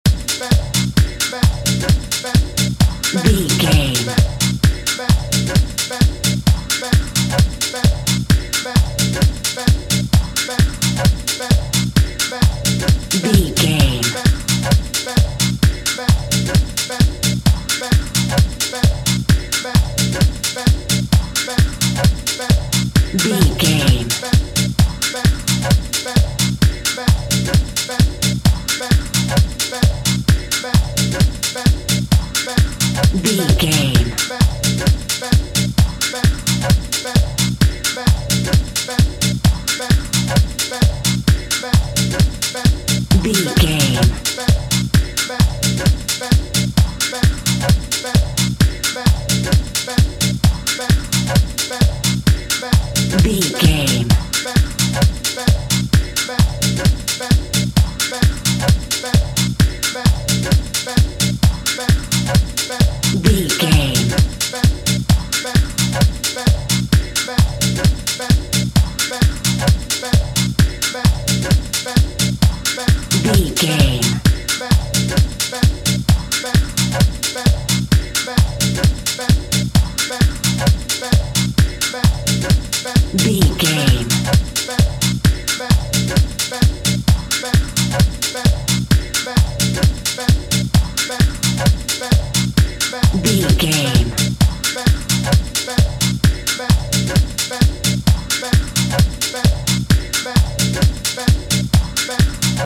Island Clubbing Music Theme.
Aeolian/Minor
Fast
groovy
smooth
futuristic
synthesiser
drum machine
house
techno
electro
electro house
synth leads
synth bass